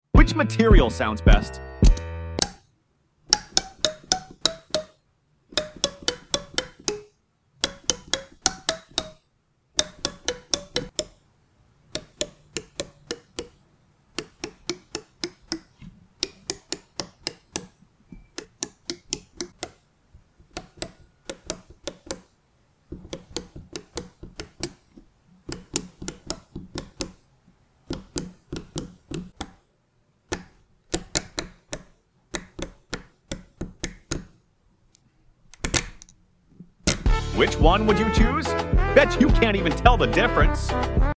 Testing musical fidget on different sound effects free download